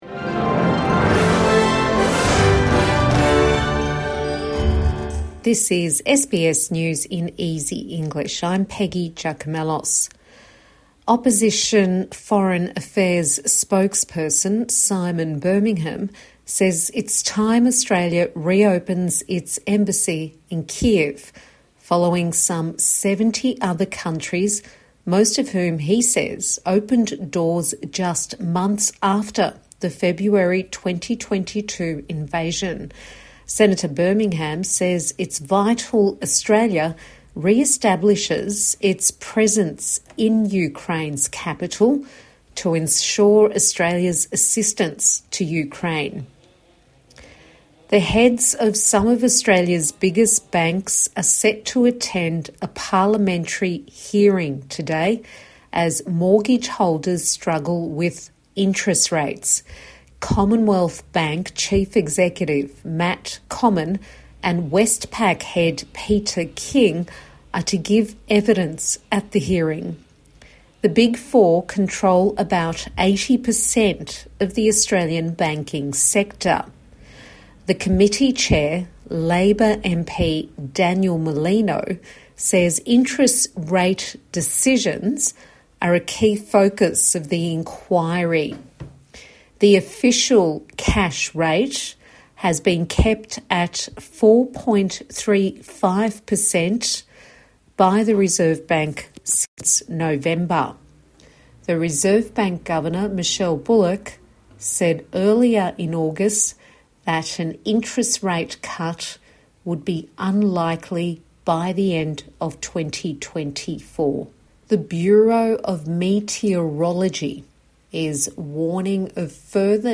A five minute bulletin for English language learners